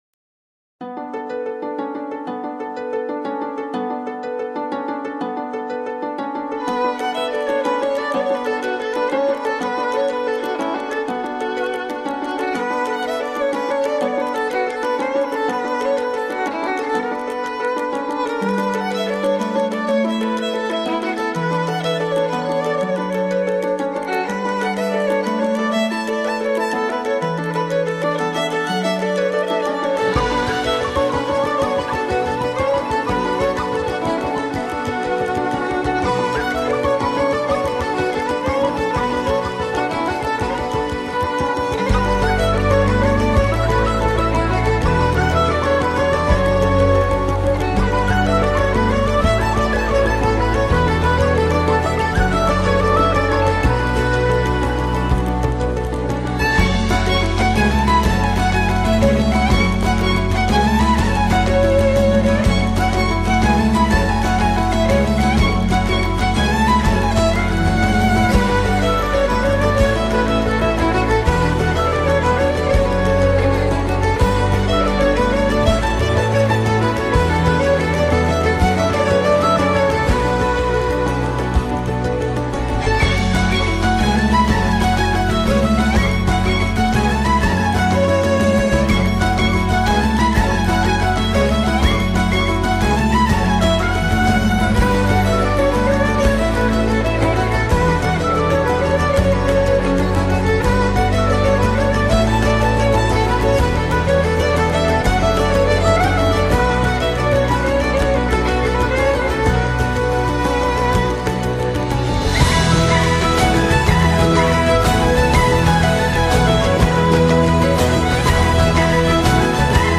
与前两张相比人声部分加大了
虽然这张唱片在风格上有不同的变化，但真实的冥想和追寻内心的方式再一次贯穿在